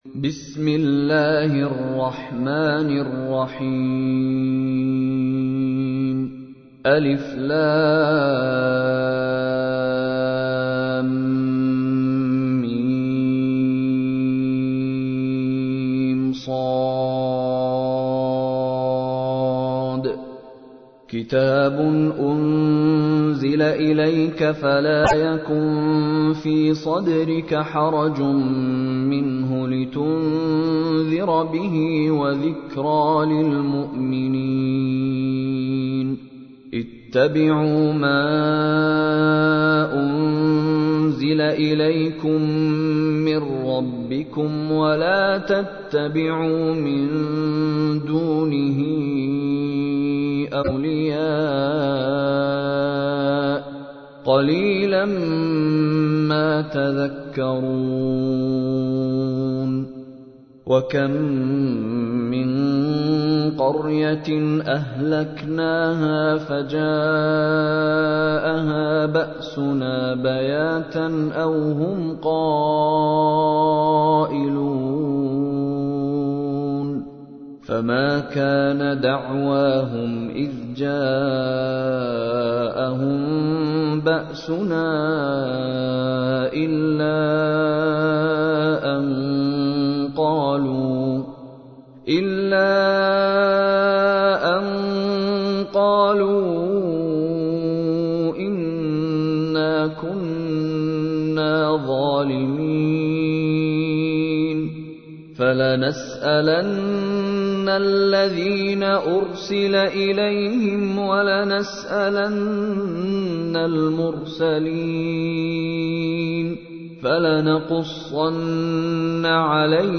تحميل : 7. سورة الأعراف / القارئ مشاري راشد العفاسي / القرآن الكريم / موقع يا حسين